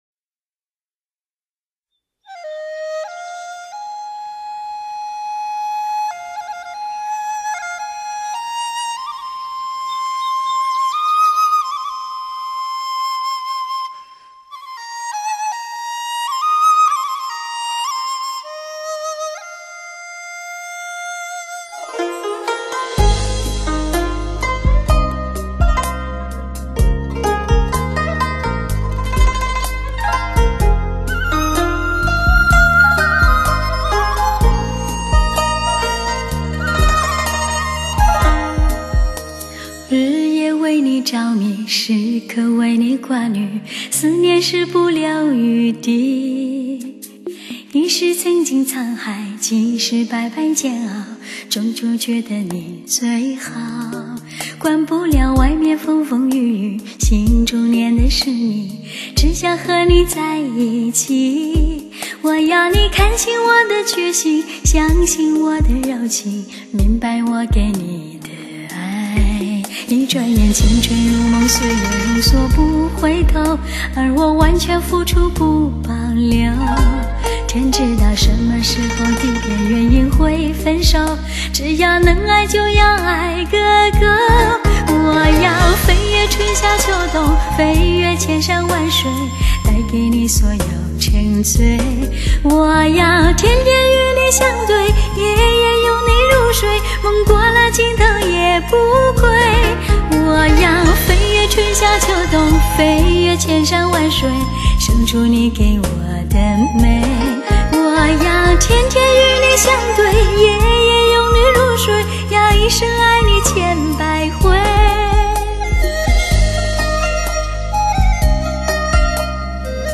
HD-AUDIO 高采样技术录制
温柔而浪漫 抒情而细腻
清澈？还是甜美？